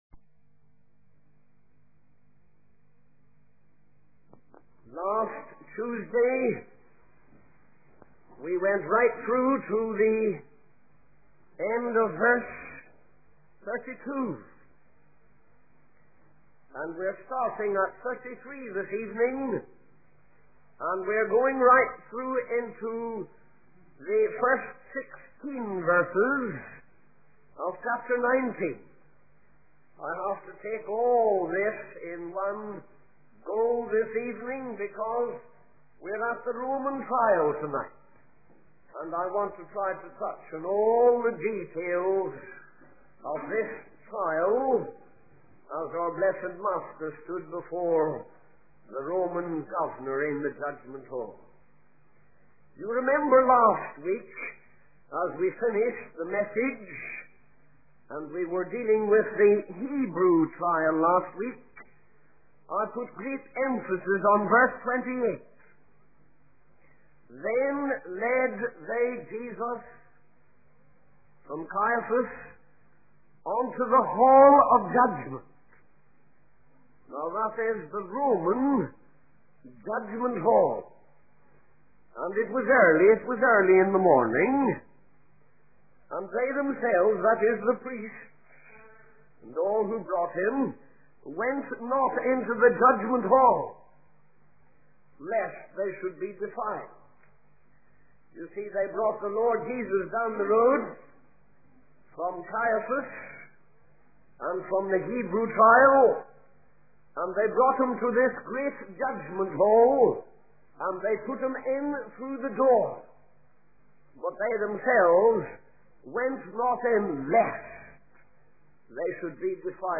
In this sermon, the preacher begins by praying for the congregation and expressing the urgency of the message. He then focuses on the interaction between Jesus and Pilate during the trial before the crucifixion.